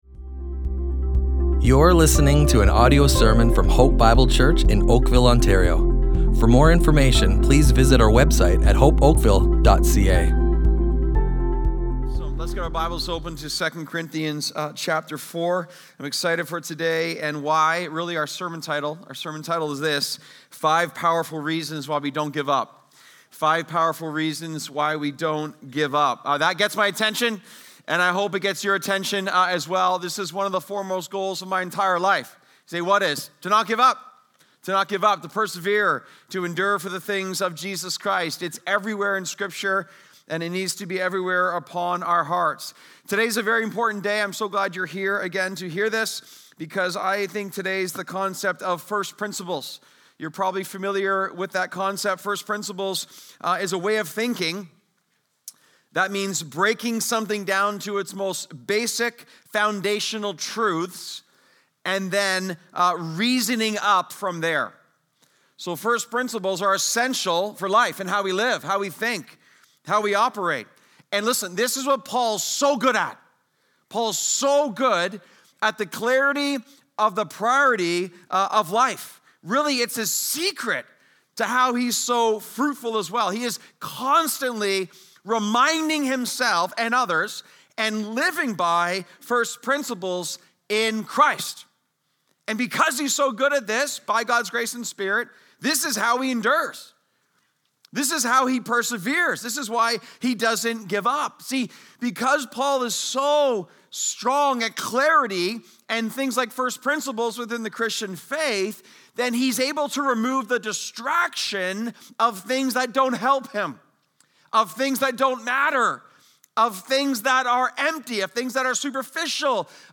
Hope Bible Church Oakville Audio Sermons Strength in Weakness // Five Powerful Reasons Why We Don't Give Up!